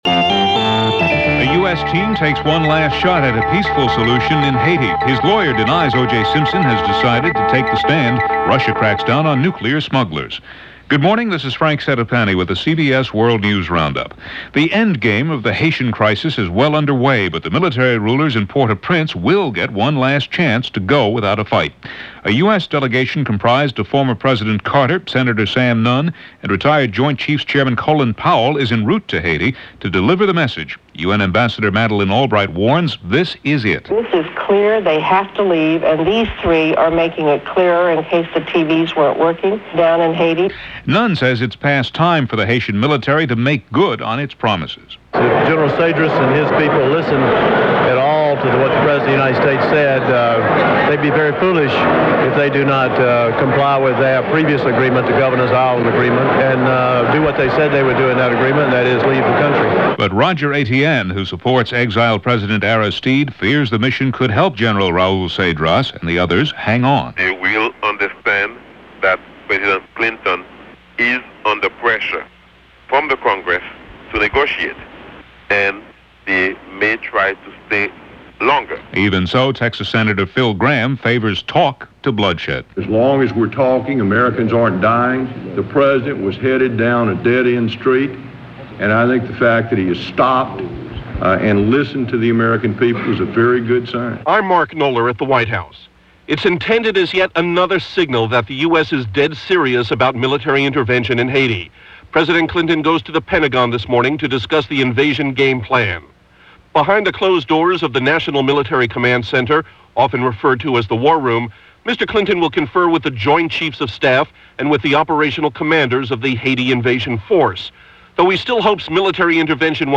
All that, and much-much more for this September 17th in 1994 from The CBS World News Roundup.